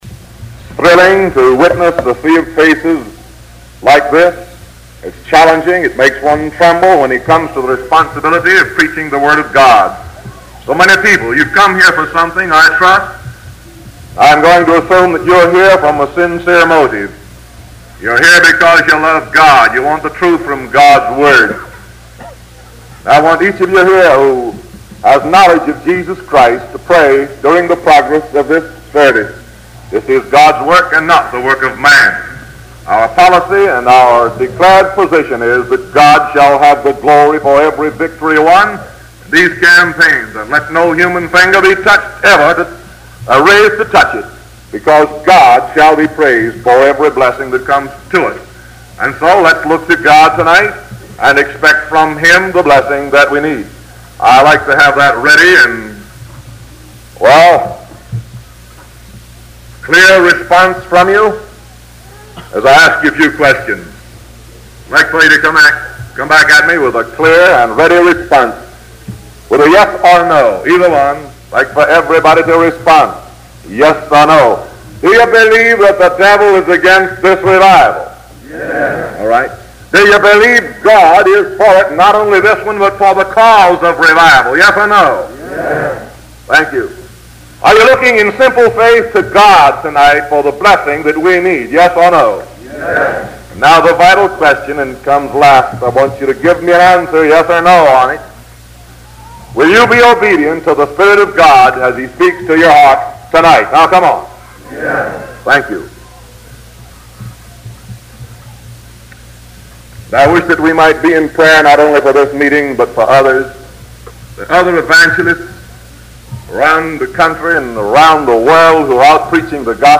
The sermon concludes with a comparison between the church of today and the early church, expressing concern about the lack of progress in winning souls for Christ.